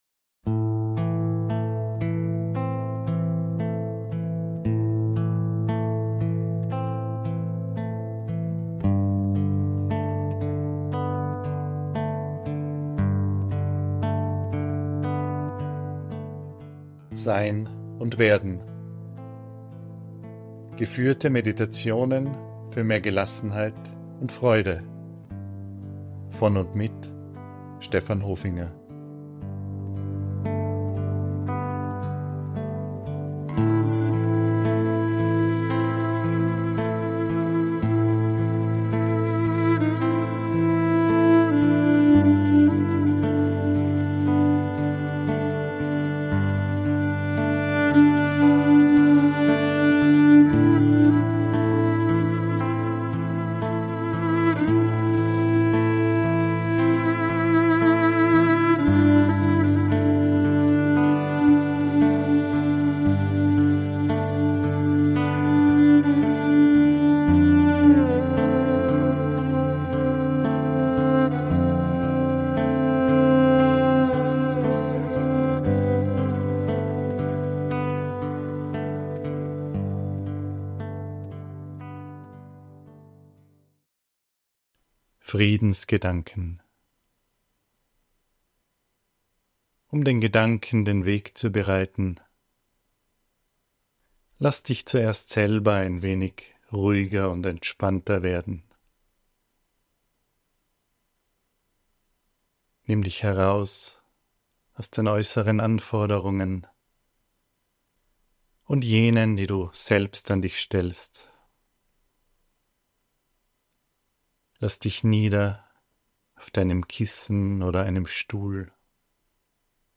Meditationen für mehr Gelassenheit und Freude